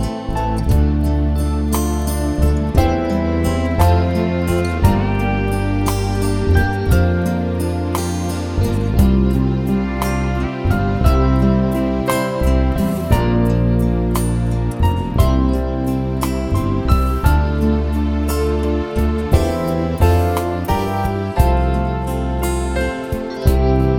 One Semitone Down Jazz / Swing 3:44 Buy £1.50